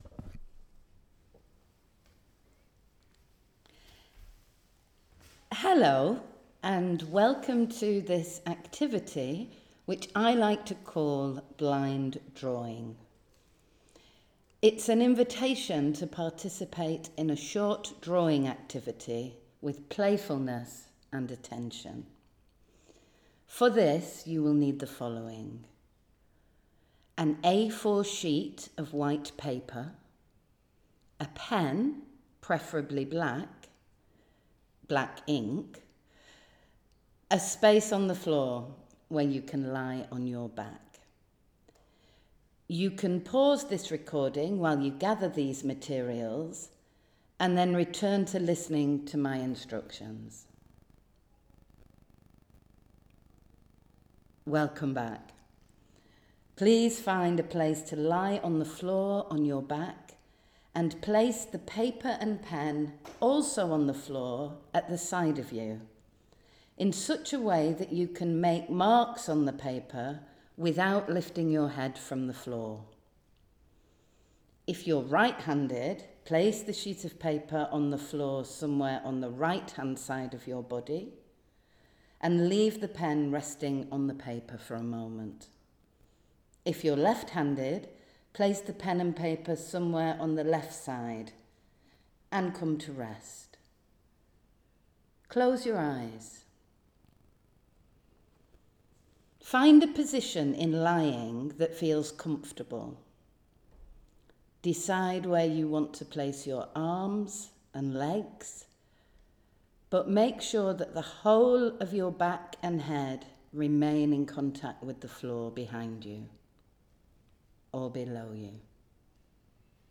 Create your own self-portrait drawing by following the 15-minute audio instructions